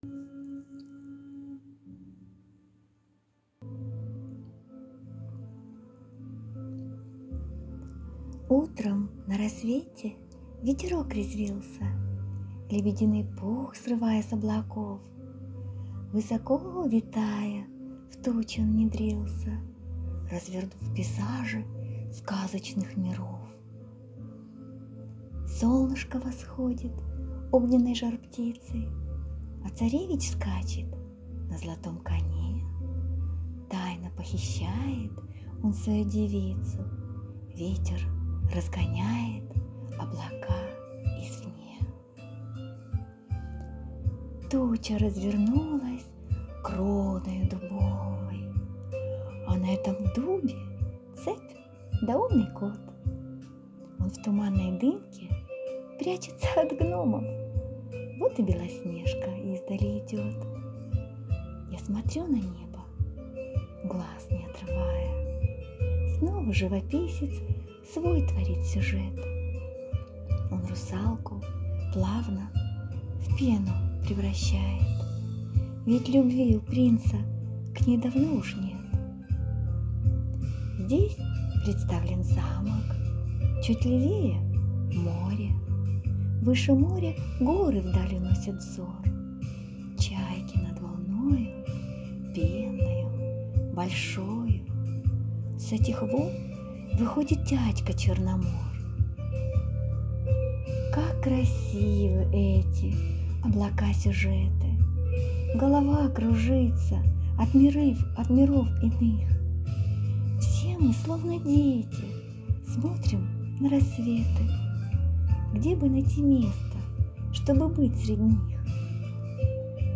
Чудовий вірш і надзвичайно приємний голос!
Гарно і дуже мелодійно! give_rose 32
Голос зачаровує!
Заворожили... tender Дуже приємна декламація твору.